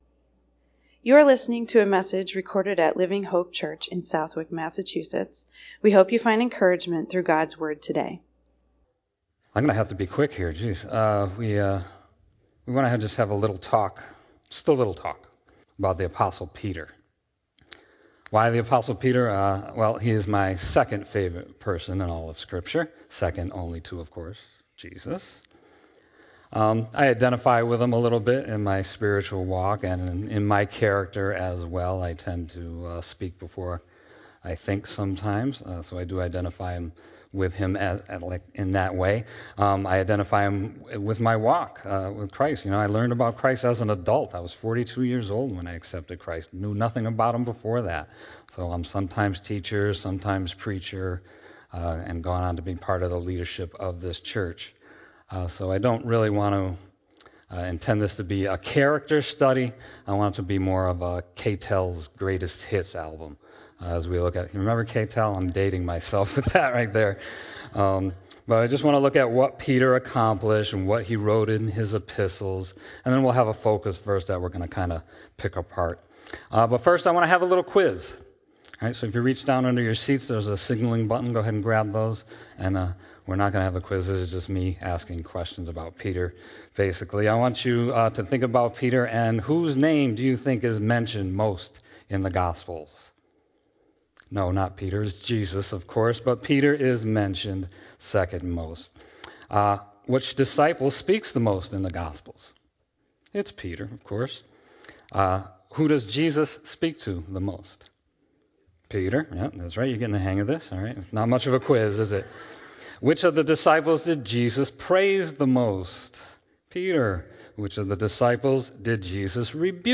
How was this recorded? Sunday messages from Living Hope Church AG located in Southwick, Massachusetts.